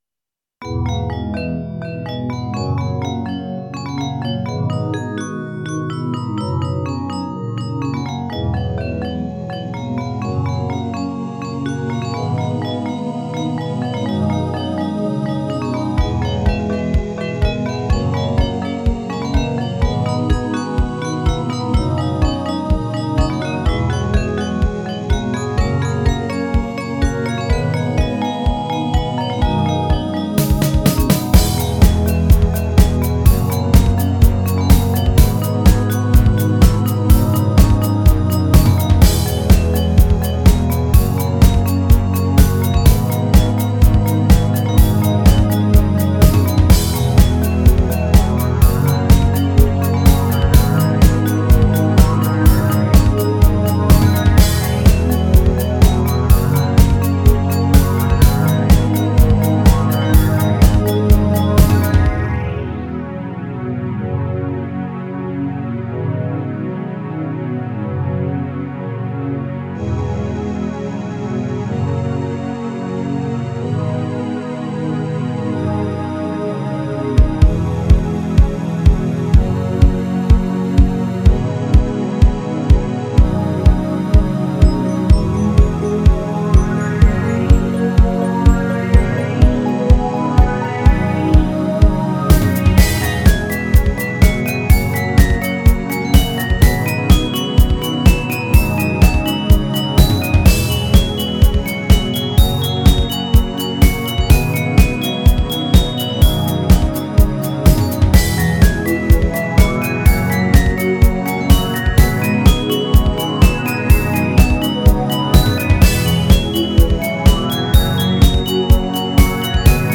神秘的・静か・悲しい